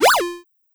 DropSFX.wav